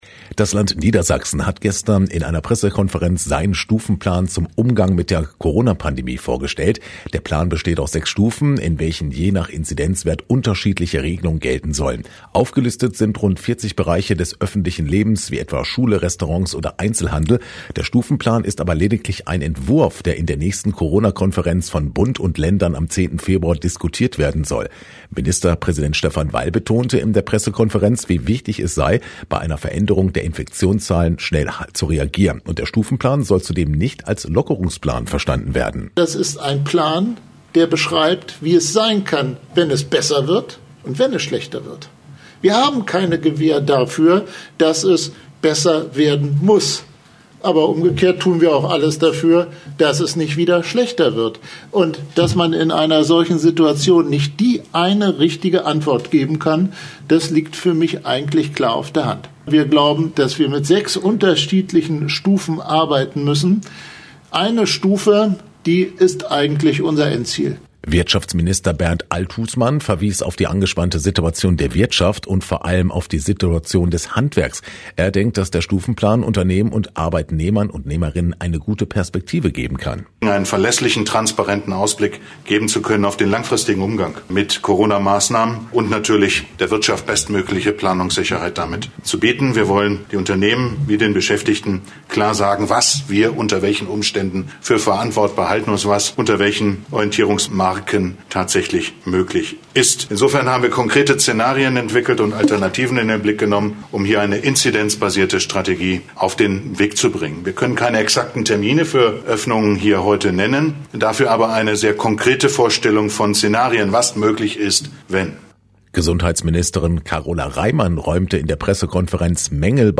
Niedersachsen: CORONA PRESSEKONFERENZ